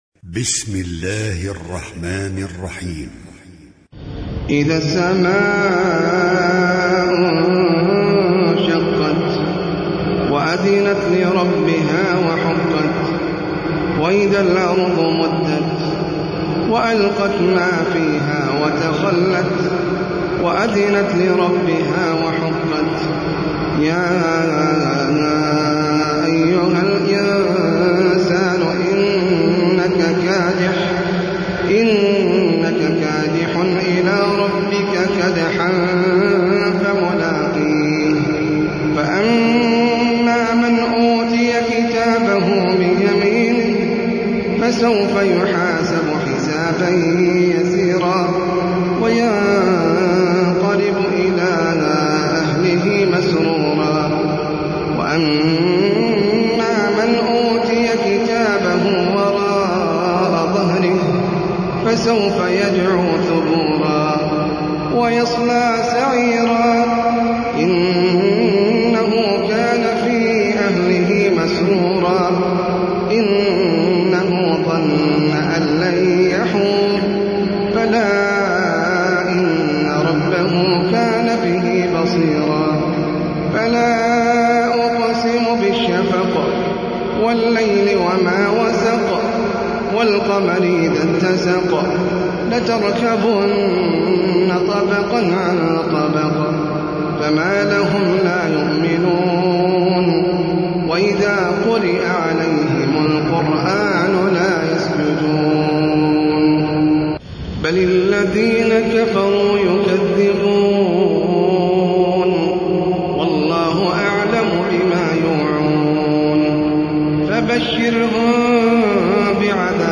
سورة الانشقاق - المصحف المرتل
جودة عالية